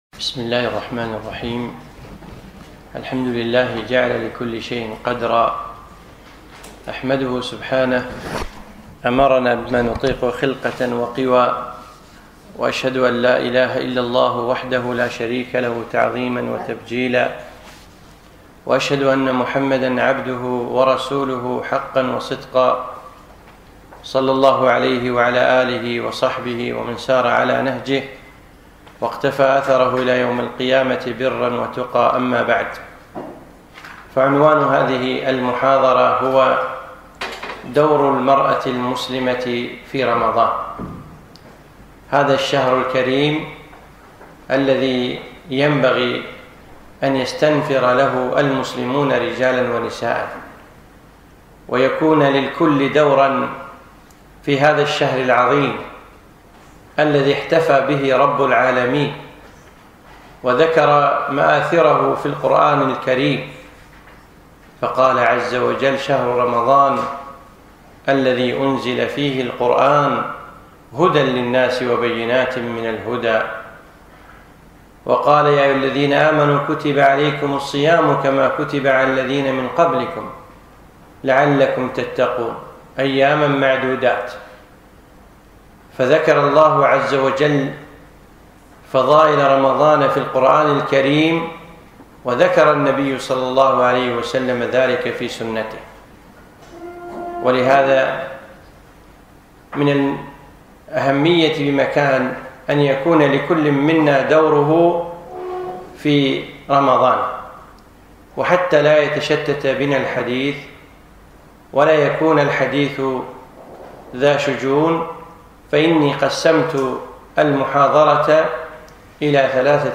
محاضرة - دور المرأة المسلمة في رمضان